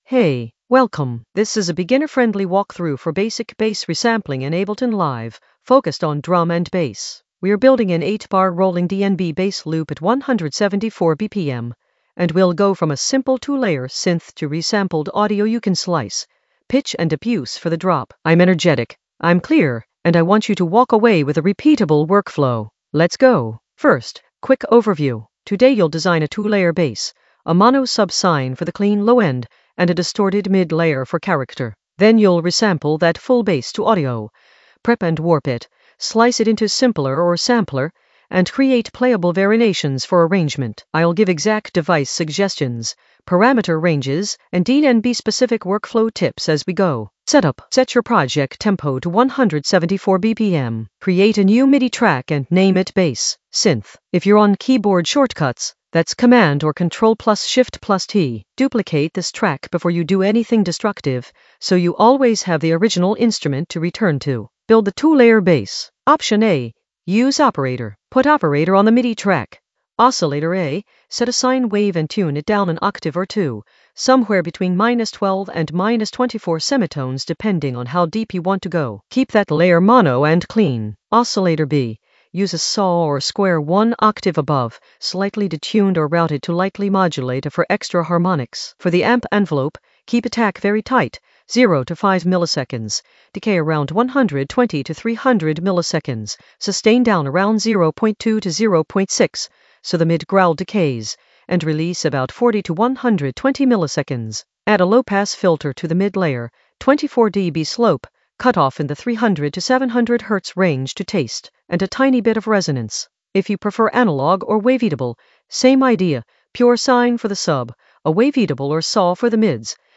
An AI-generated beginner Ableton lesson focused on Basic bass resampling in the Basslines area of drum and bass production.
Narrated lesson audio
The voice track includes the tutorial plus extra teacher commentary.